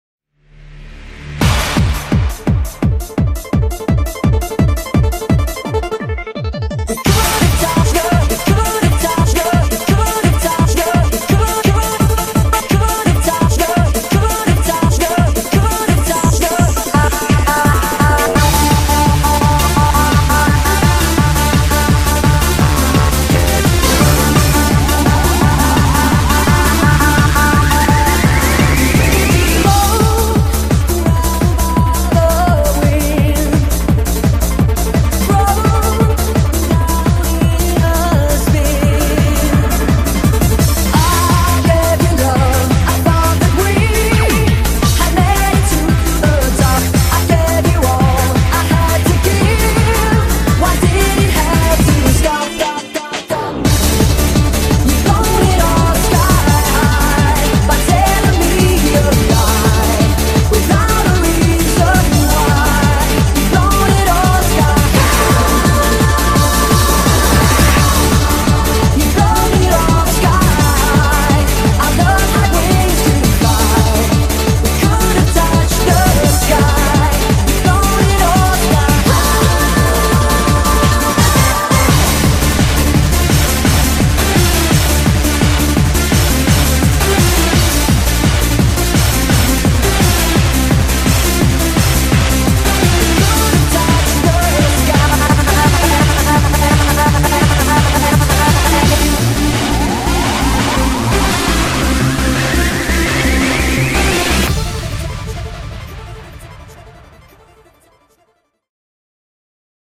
BPM170
Audio QualityPerfect (Low Quality)
its a speed remix